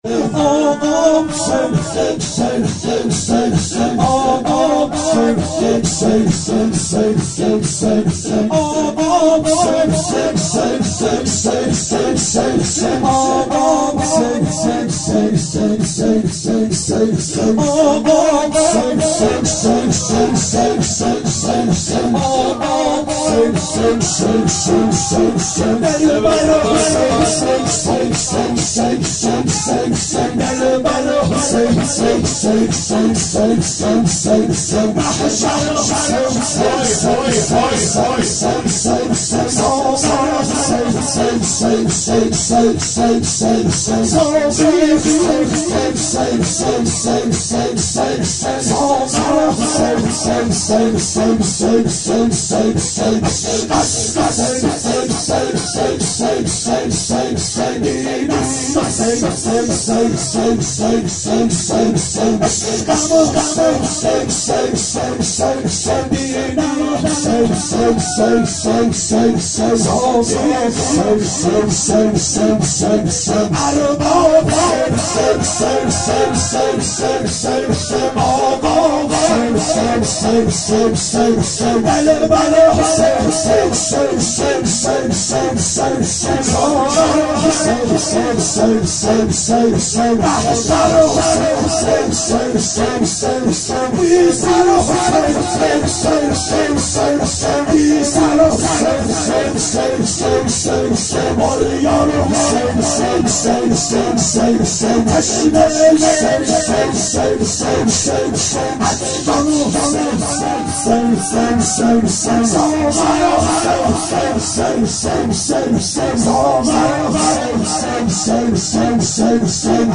شب شهادت امام هادی علیه السلام 92 محفل شیفتگان حضرت رقیه سلام الله علیها